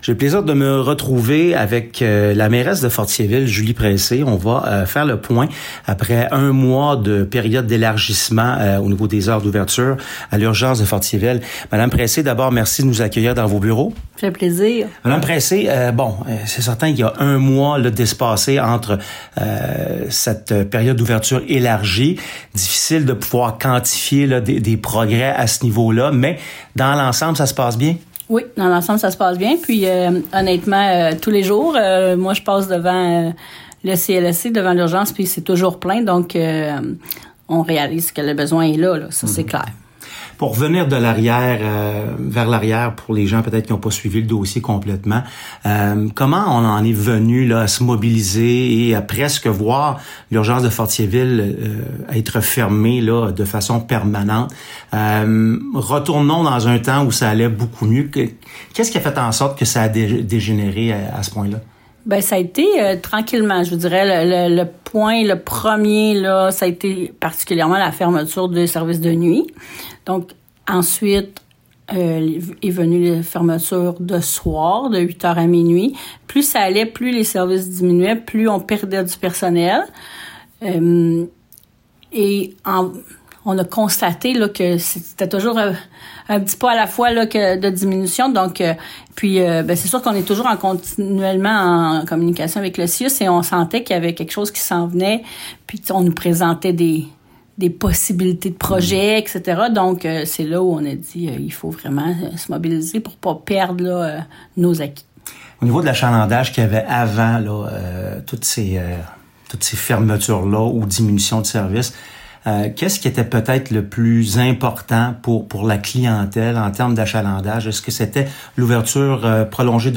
Entrevue | « C’est toujours fragile », la situation avec l’urgence de Fortierville